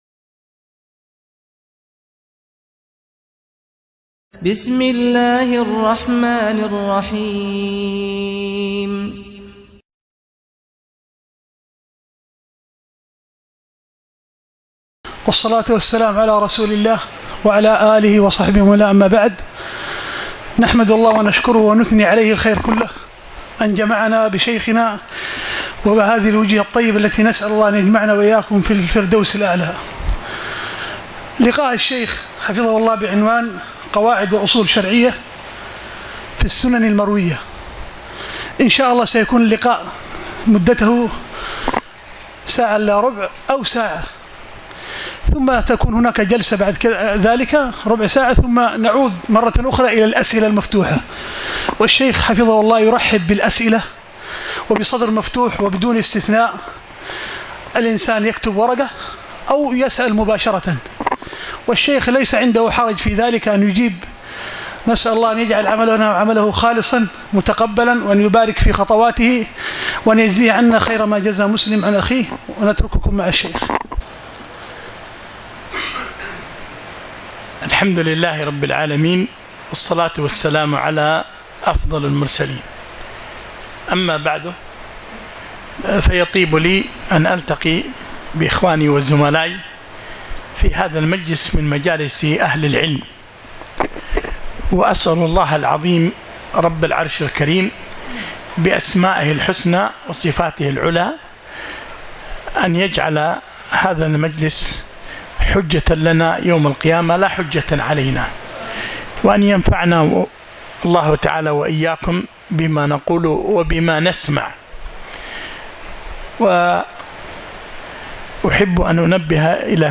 محاضرة - قواعد الأصول الشرعية في السنن المروية